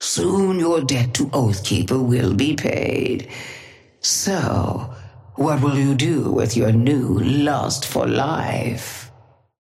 Sapphire Flame voice line - Soon your debt to Oathkeeper will be paid.
Patron_female_ally_ghost_oathkeeper_5i_start_03.mp3